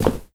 step2.wav